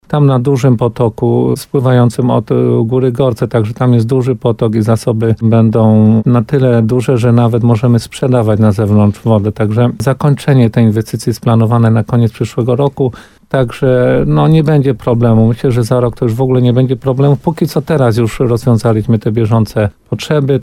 – Potrzeby są jednak większe – mówi wójt Władysław Sadowski.